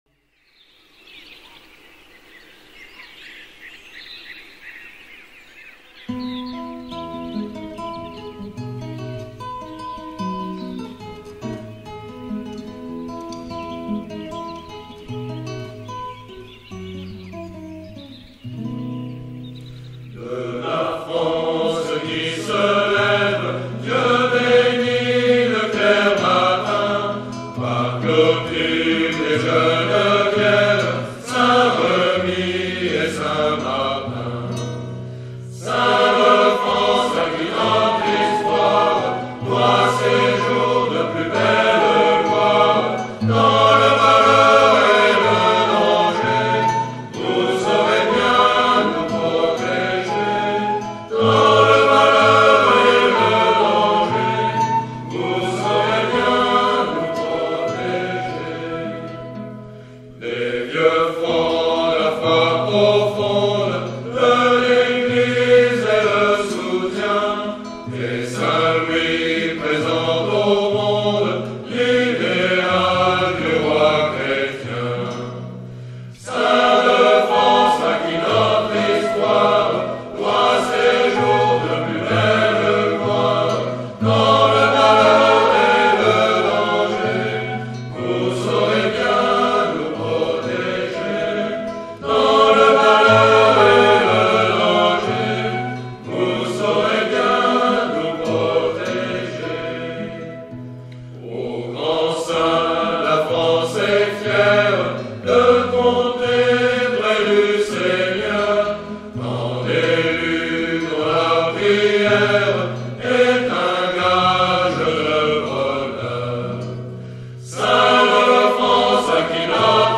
SaintsDeFrance-choeur-montjoie-saint-denis.mp3